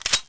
p228_sliderelease.wav